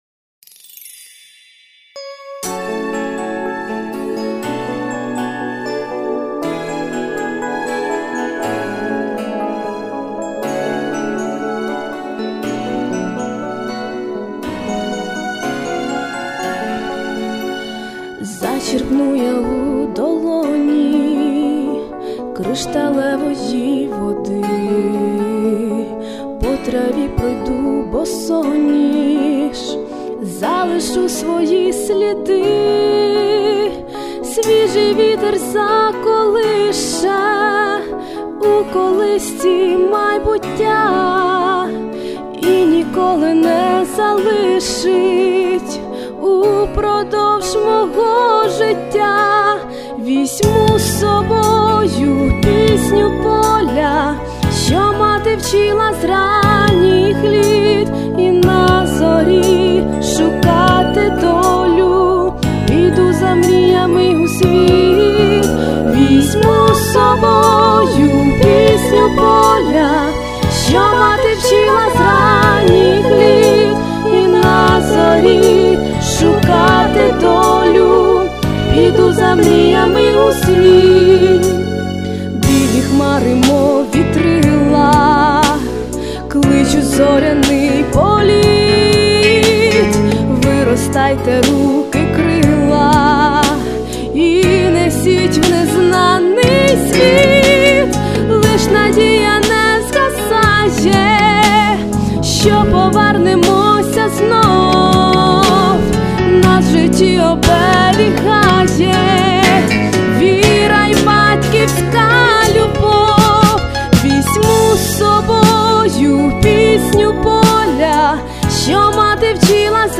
Рубрика: Поезія, Авторська пісня